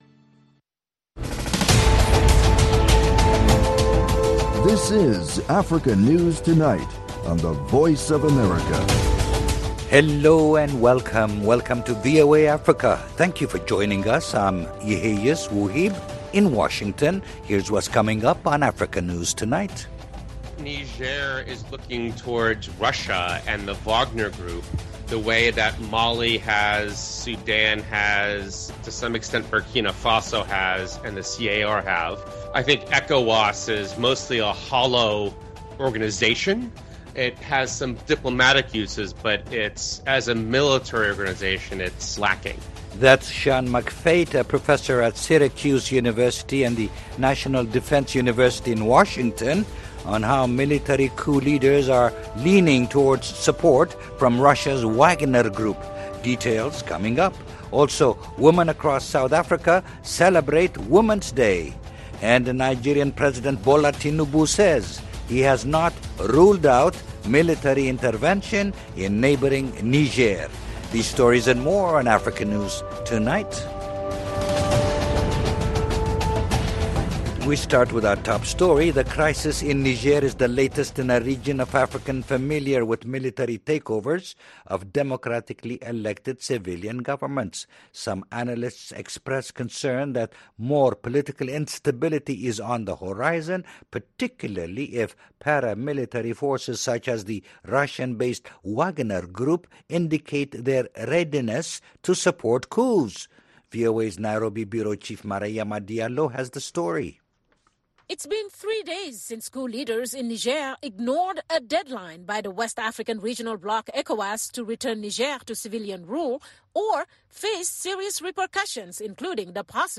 Africa News Tonight is a lively news magazine show featuring VOA correspondent reports, interviews with African officials, opposition leaders, NGOs and human rights activists. News feature stories look at science and technology, environmental issues, humanitarian topics, and the African diaspora.